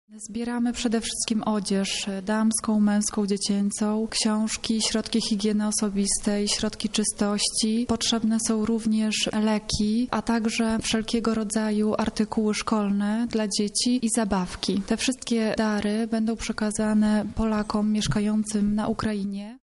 O tym czego potrzeba najbardziej mówi